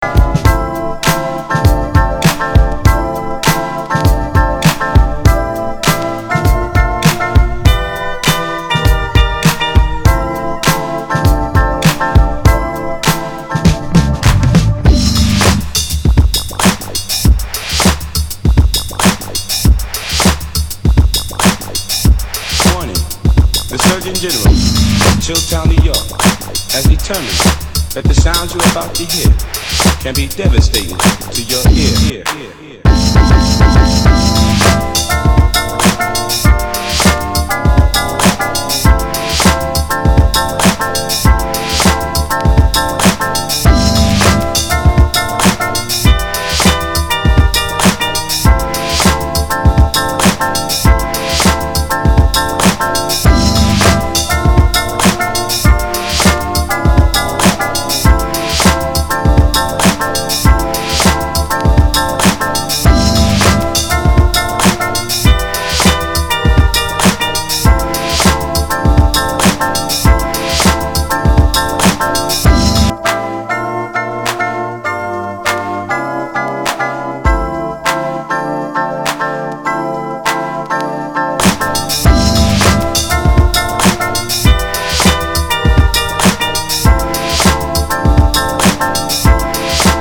ともに酩酊感溢れる昨今の暑すぎる夏にピッタリのフロア爆弾です。